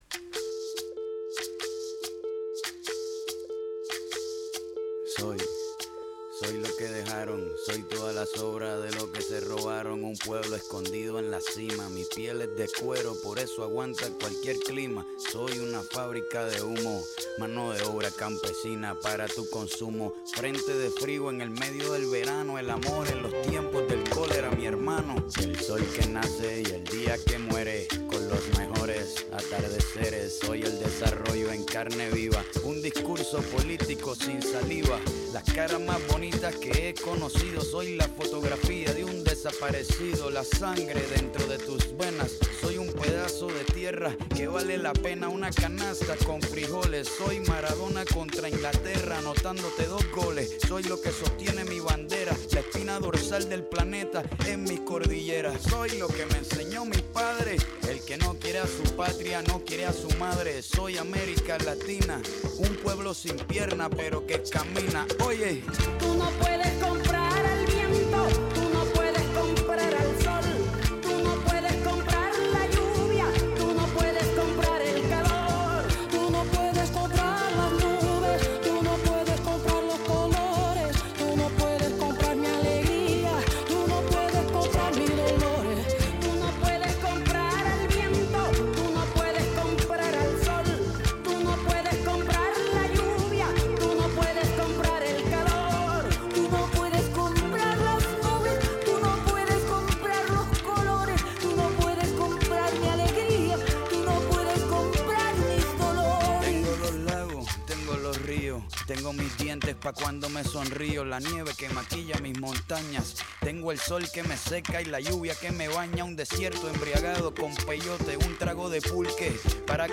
Η ΦΩΝΗ ΤΗΣ ΕΛΛΑΔΑΣ Αποτυπωμα ΕΝΗΜΕΡΩΣΗ Ενημέρωση Πολιτισμός ΣΥΝΕΝΤΕΥΞΕΙΣ Συνεντεύξεις κοινωνικα